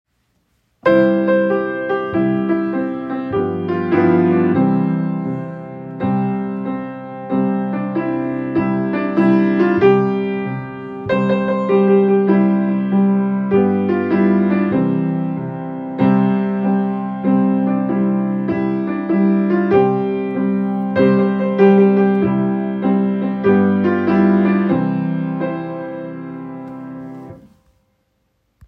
Audio: Row Your Boat - Accompaniment
Row-Row-Your-Boat-piano-accompaniment.m4a